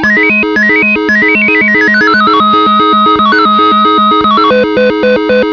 Little ditty number one
is too groovy